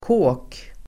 Uttal: [kå:k]